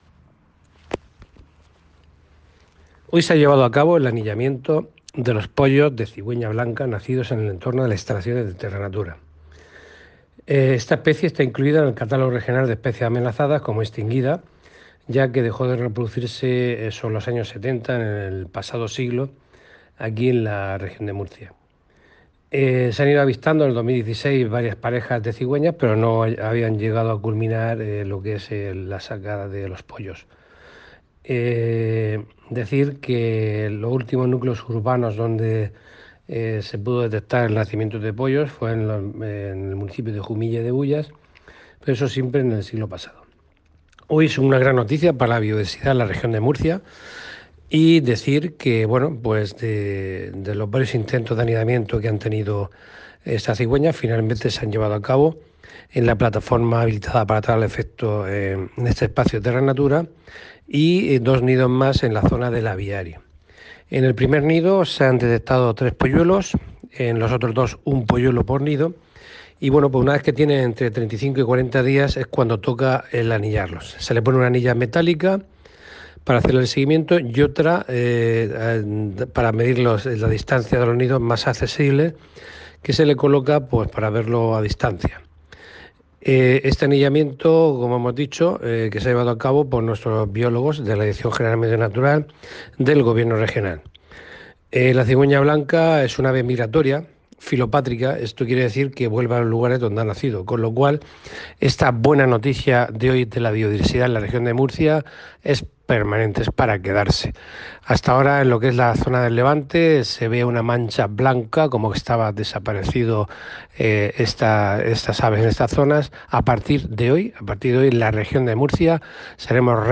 Contenidos Asociados: Declaraciones del direcvtor general del Medio Natural sobre el anillamiento de pollos de cigüeña blanca (Documento [.mp3] 2,09 MB) Destacados Conciliación laboral (SMAC) e-Tributos Pago a Acreedores Participación ciudadana Canal Mar Menor © Todos los derechos reservados.